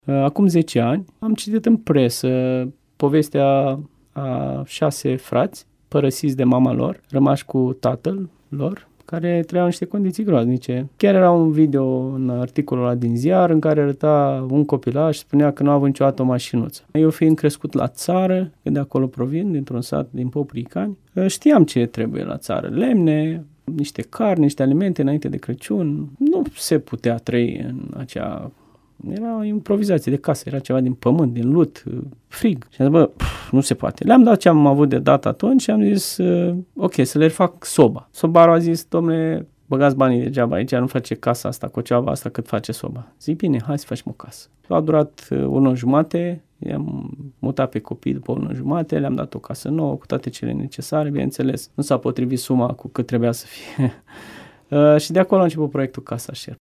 Într-un interviu acordat recent postului nostru de radio